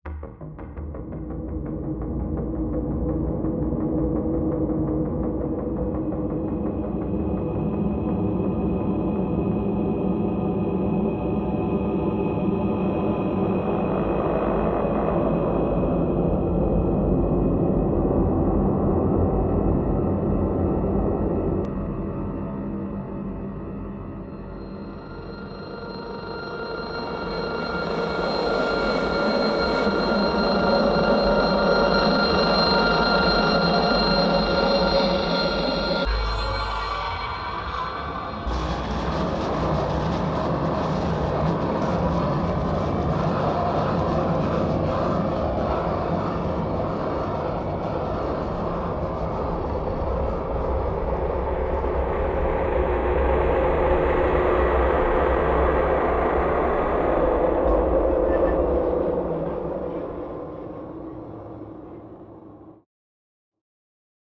• 黑暗氛围（Dark Atmospheres）
• 机械低频音景（Mechanical Drones）
• 工业冲击音效（Industrial Impacts）
• 核心风格黑暗工业、反乌托邦、紧张氛围、机械质感、科幻惊悚